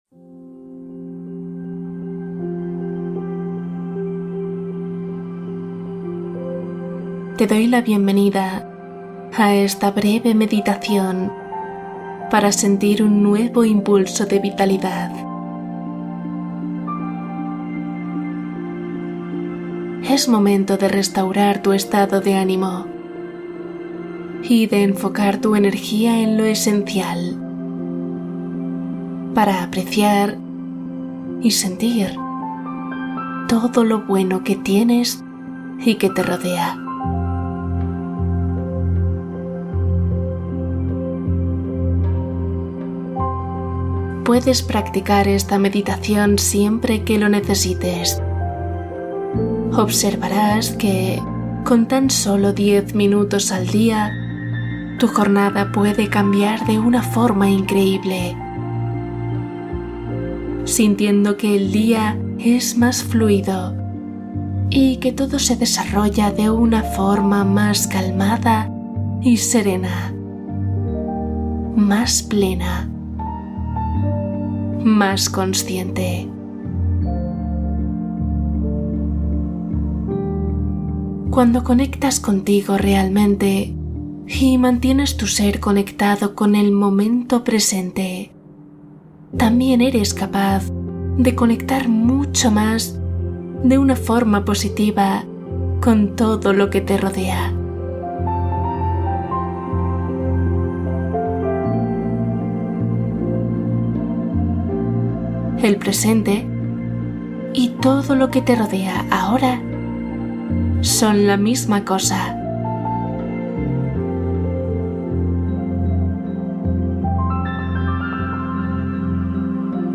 Meditación guiada 10 minutos | Armoniza tu día y despierta feliz